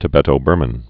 (tĭ-bĕtō-bûrmən)